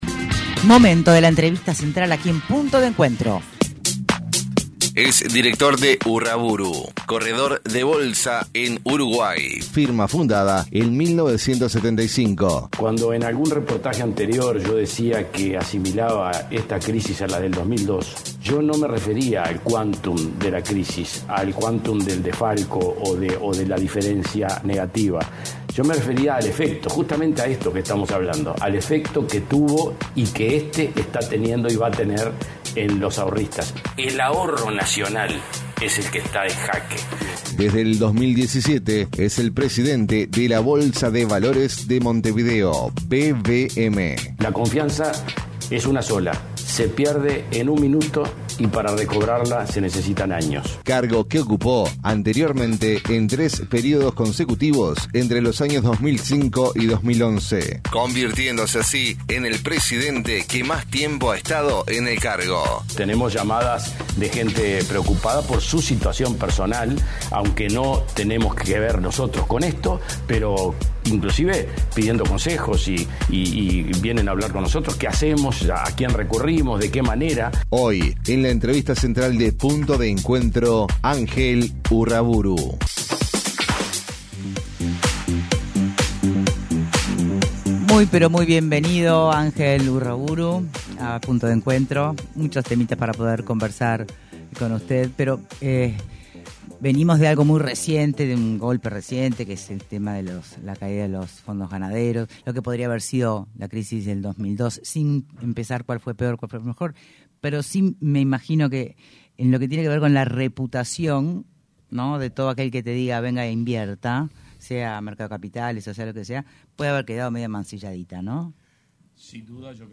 En entrevista con Punto de Encuentro